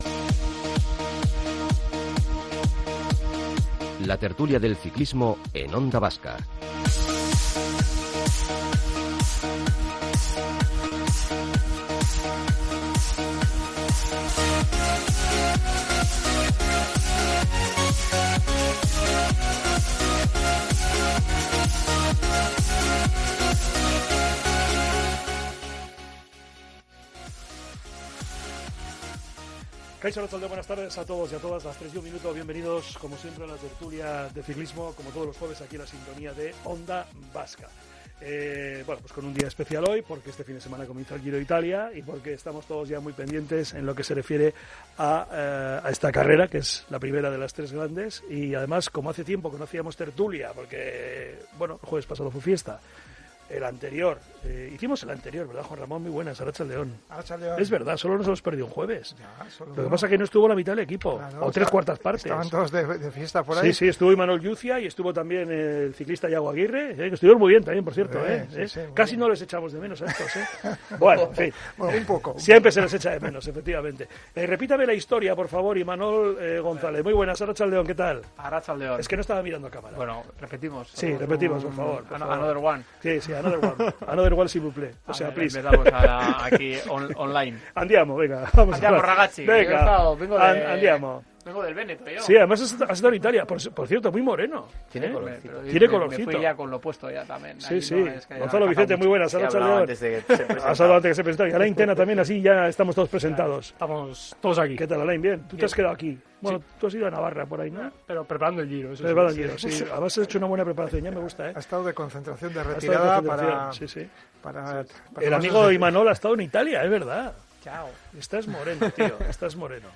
Onda Vasca Bizkaia en directo